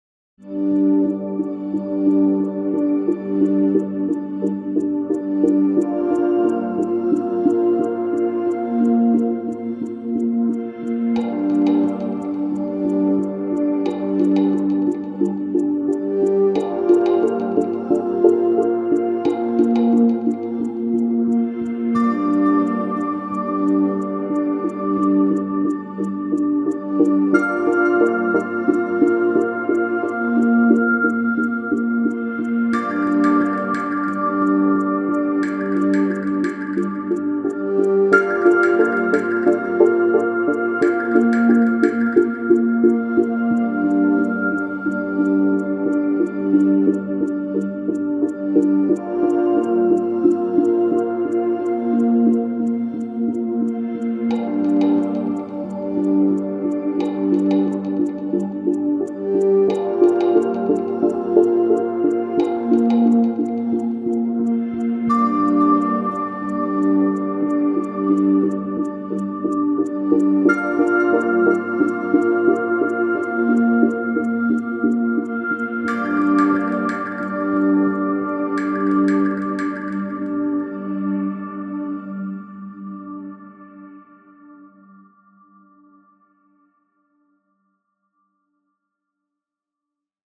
Synth ambient track for puzzle.
Synth ambient slow-paced track for puzzle.